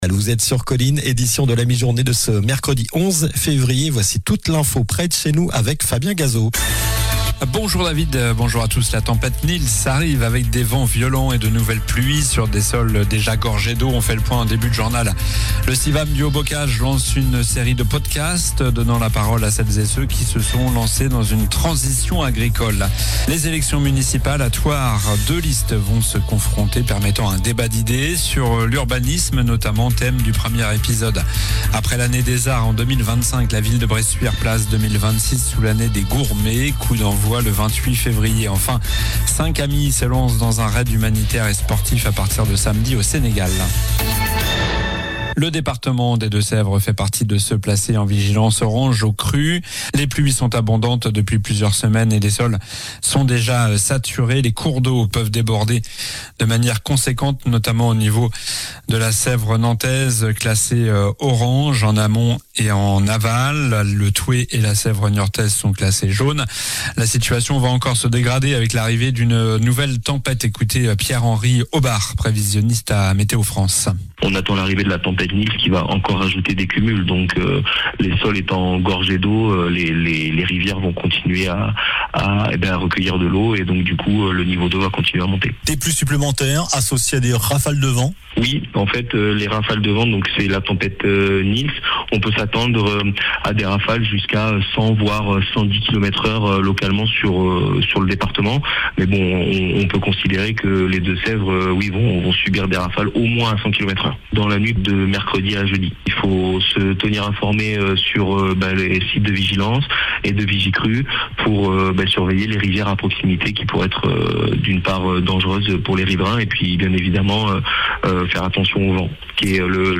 Journal du mercredi 11 février (midi)